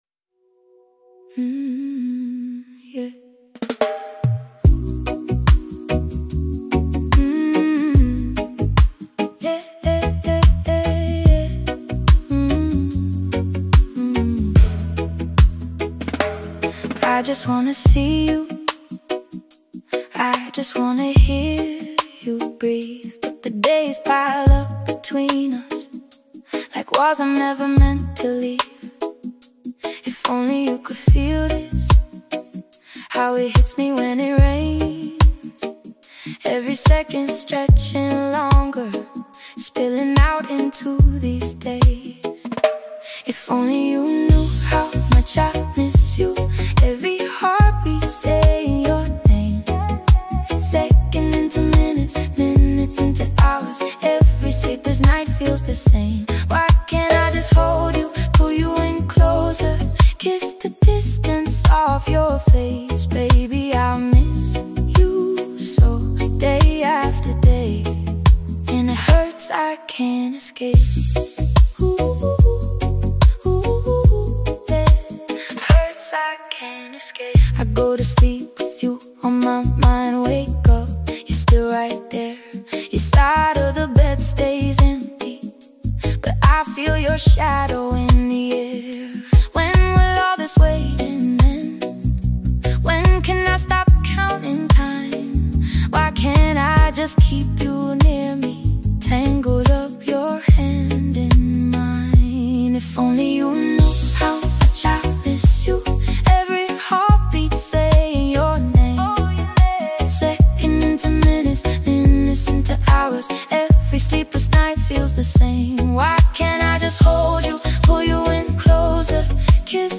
This one settles in quietly and stays there.